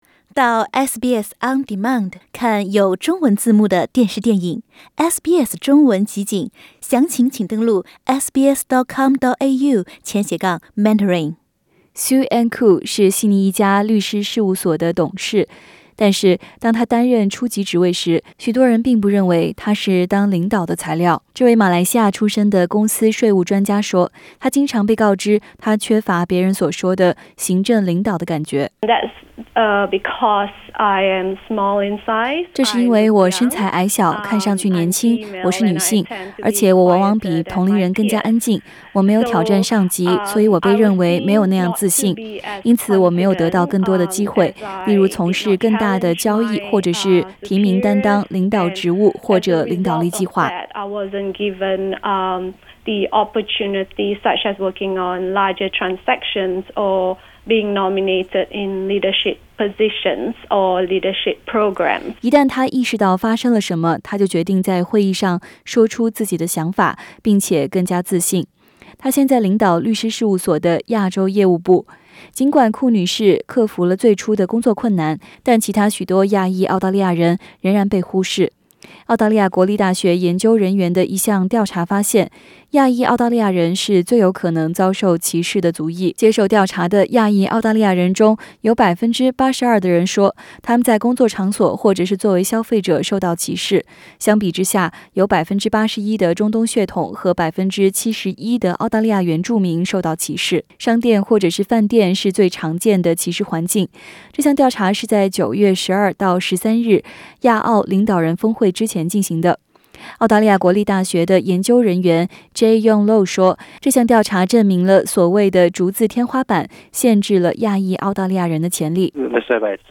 Source: AAP SBS 普通话电台 View Podcast Series Follow and Subscribe Apple Podcasts YouTube Spotify Download (8.77MB) Download the SBS Audio app Available on iOS and Android 在澳大利亚的亚洲人是在这里最有可能遭受歧视的族裔。 这是根据对所谓的“竹子天花板”，进行的一项新研究得出的结果。